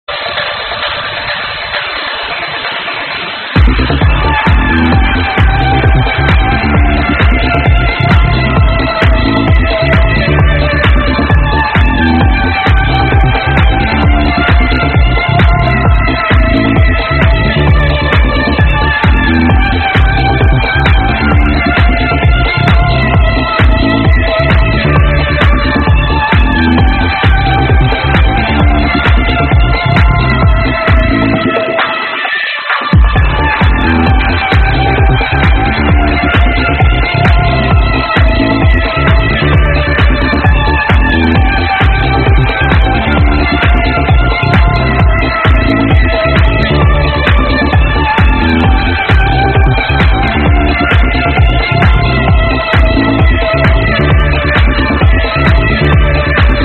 Sick bassline in this one